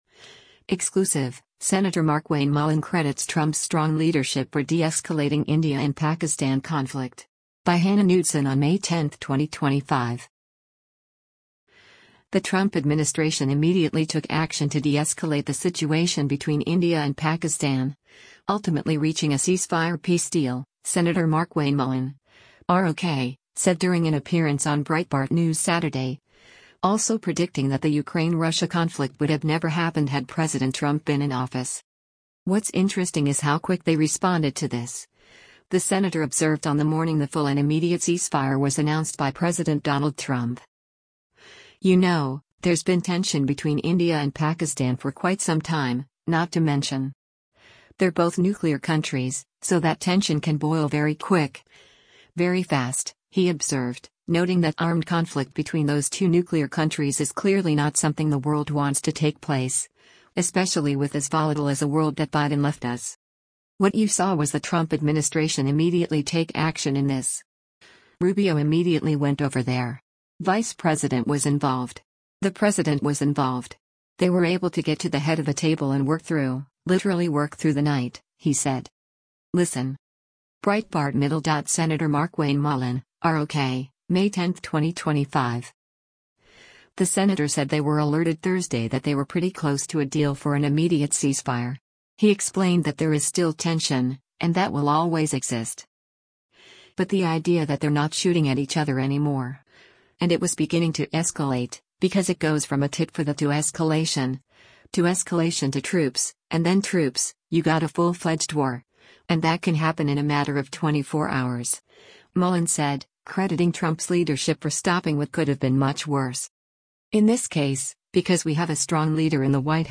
The Trump administration “immediately” took action to deescalate the situation between India and Pakistan, ultimately reaching a ceasefire peace deal, Sen. Markwayne Mullin (R-OK) said during an appearance on Breitbart News Saturday, also predicting that the Ukraine-Russia conflict would have never happened had President Trump been in office.